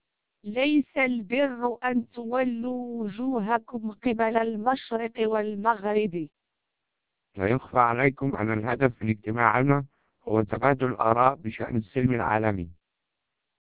Speech Samples (WAV-files).
All independent listeners preferred the TWELP vocoder, noting its superior quality, clarity, naturalness, and speech intelligibility.